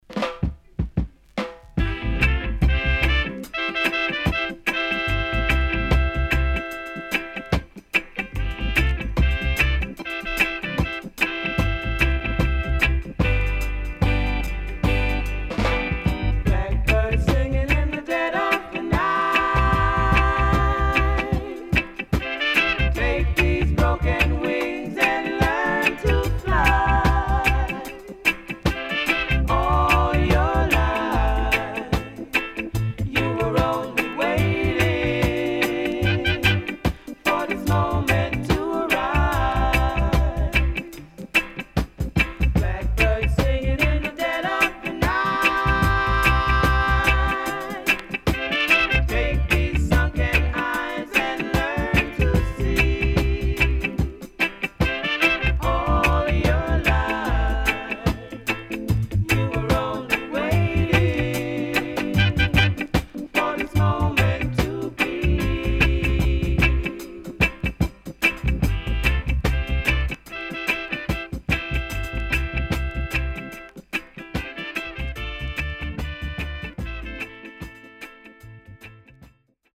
SIDE A:少しチリノイズ、プチノイズ入りますが良好です。